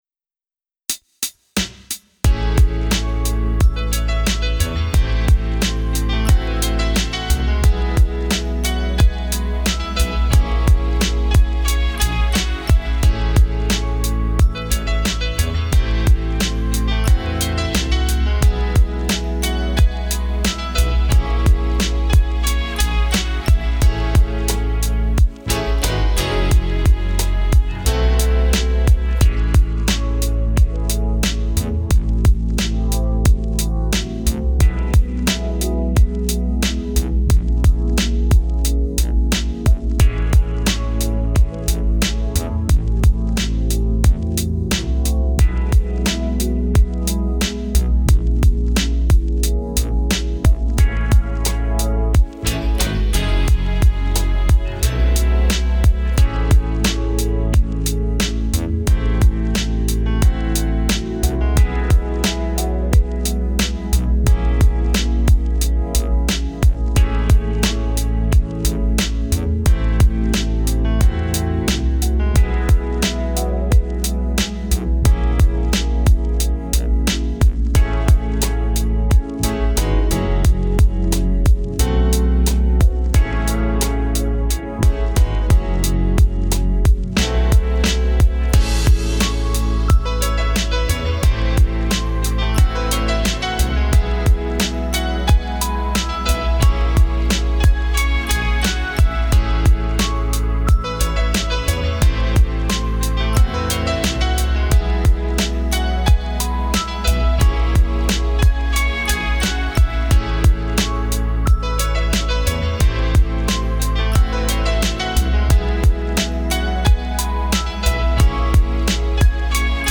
Something I'm working on (music) I made this!
if you wanted notes, I'd suggest easing off on the compression a bit. but it's a bloody lovely tune.
And also a lot of 70s soul jazz.
Edit: a spoken word comes in later (too late IMHO)
Drums need more variation but you know that.